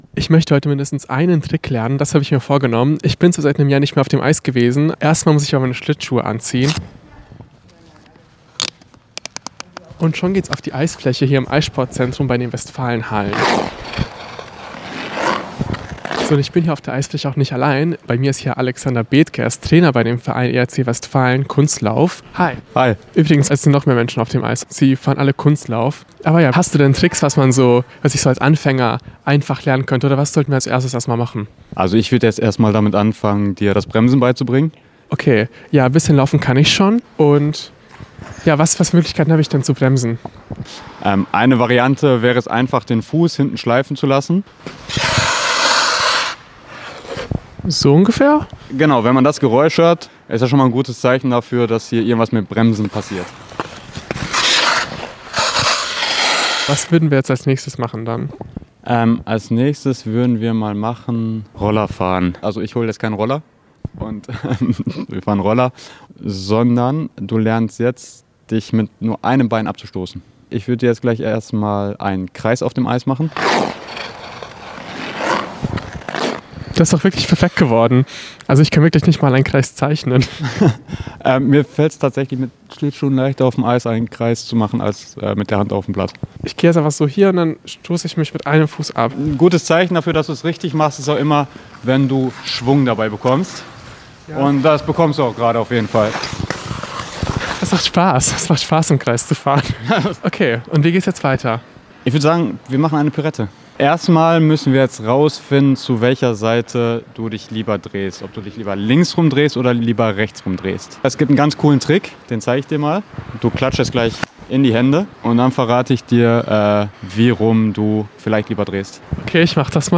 Serie: Reportage